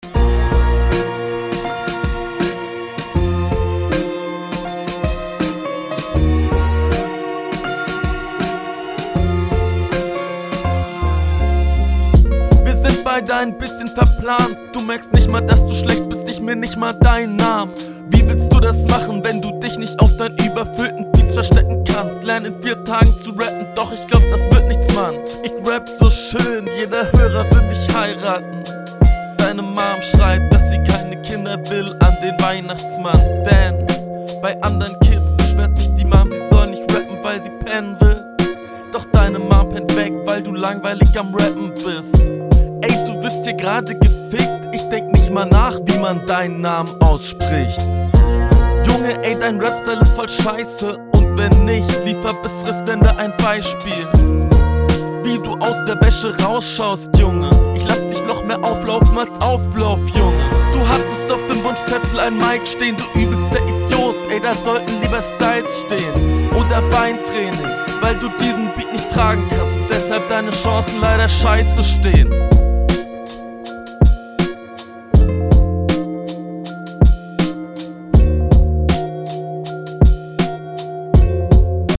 Yo auf dem Beat liegt es dir mehr zu rappen merkt man, aber auch hier …
Flowlich hast du dich hier etwas gesteigert.